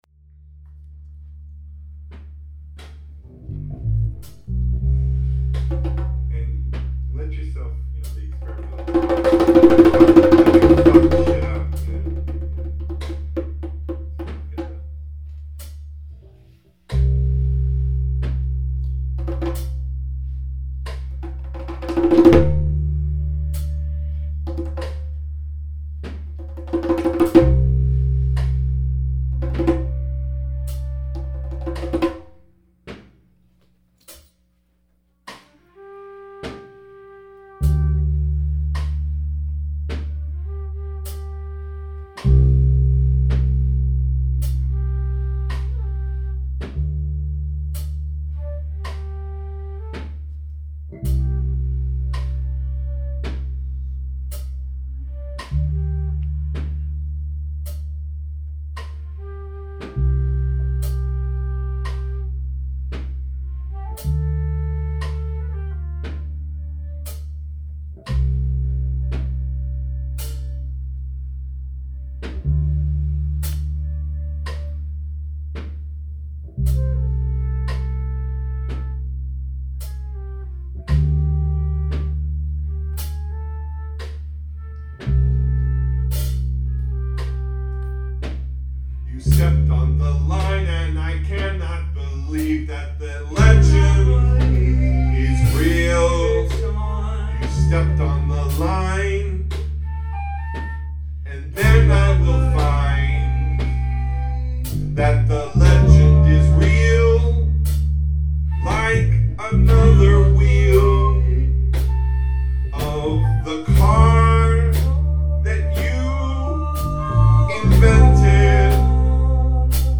ALL MUSIC IS IMPROVISED ON SITE
voice/guitar
bass
flute
drums
sitar